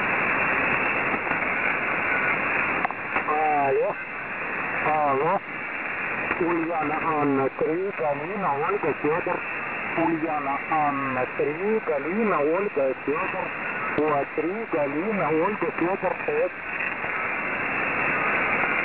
Сигнал по передаче лучше, чем до поломки аппарата. Хотя, сейчас внимательней прослушиваю, в последней прошивке звук потрескивает.